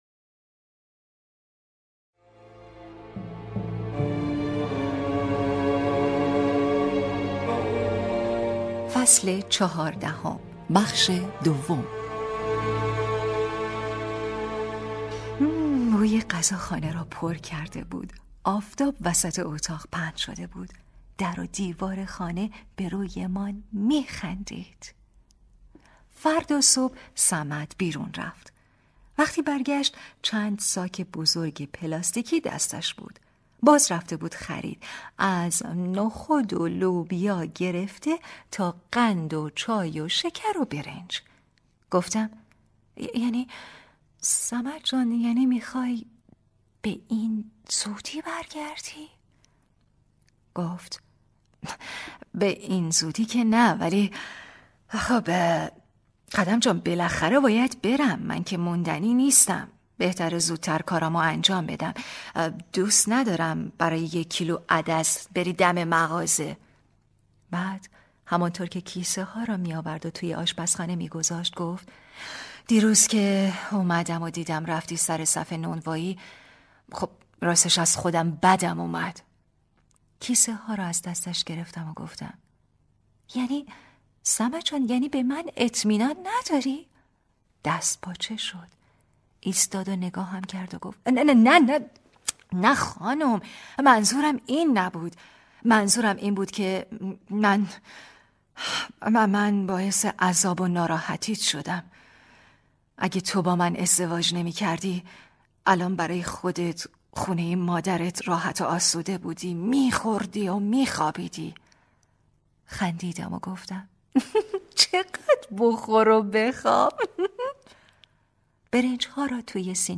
کتاب صوتی | دختر شینا (12)